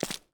Footstep_Dirt_07.wav